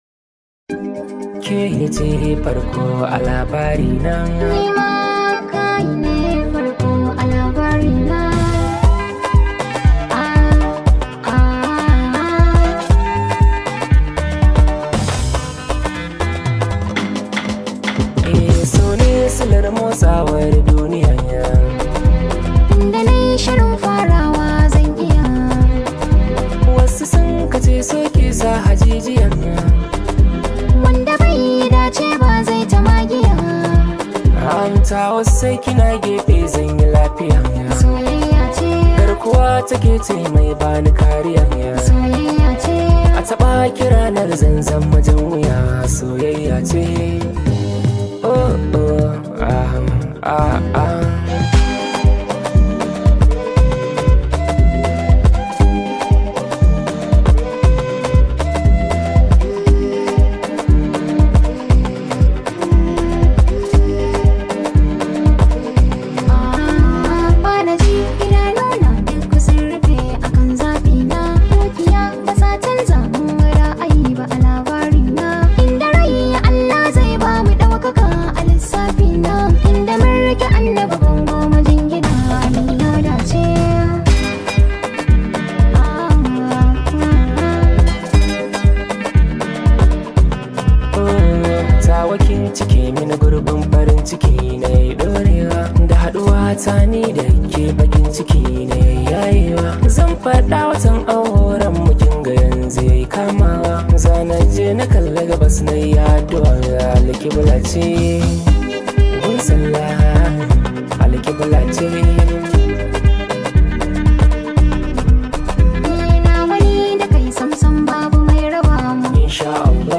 Hausa Songs
blends Afrobeat with contemporary sounds
With its upbeat tempo and catchy sounds